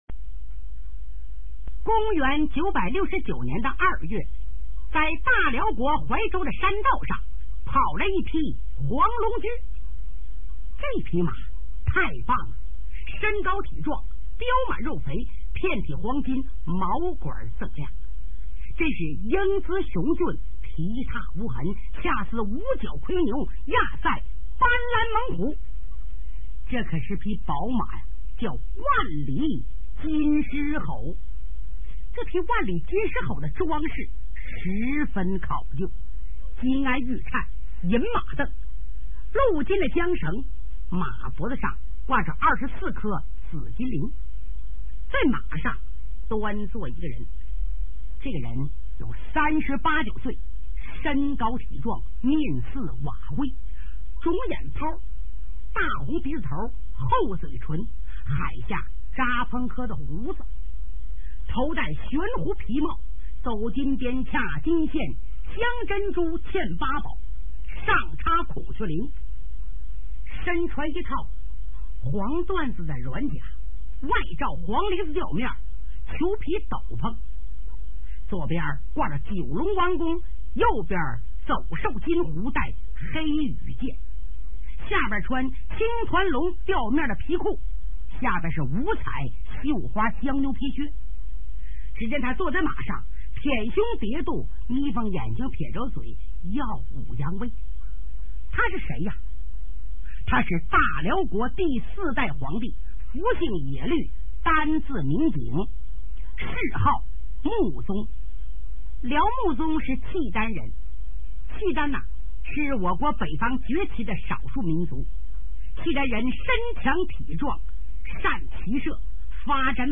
[28/12/2010]【评书连播】《契丹萧太后》[全70回]（播讲 刘兰芳）[24K MP3][115网盘]